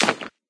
plasticstone3.ogg